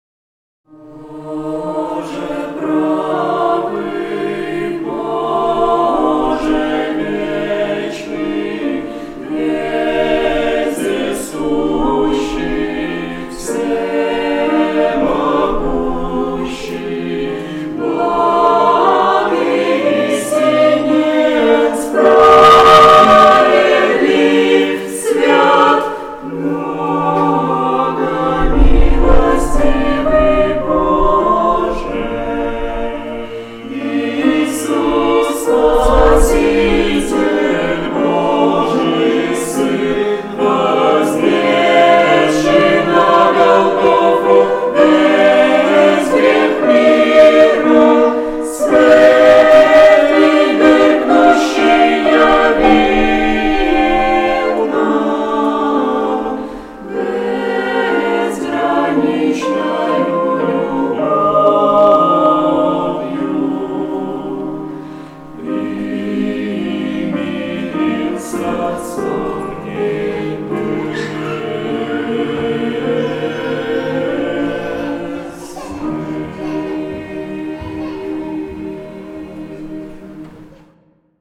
03-07-16 / Боже правый (Молодёжное прославление)